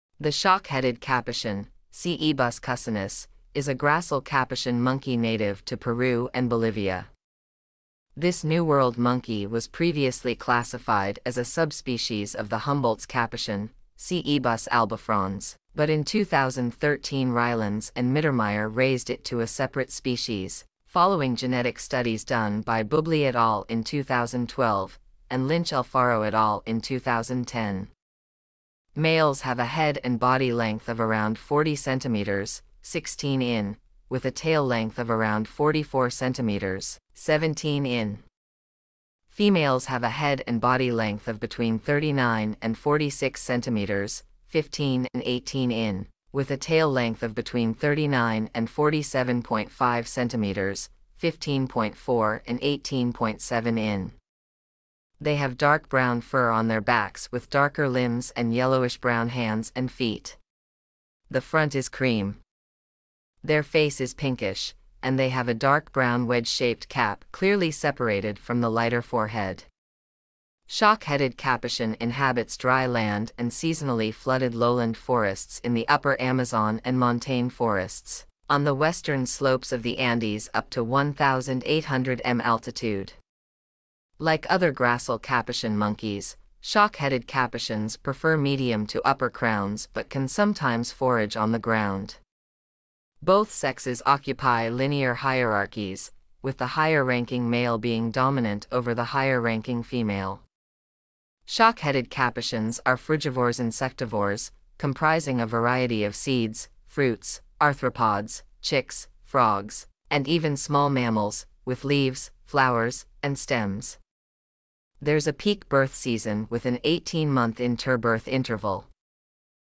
Shock-headed Capuchin
Shock-headed-Capuchin.mp3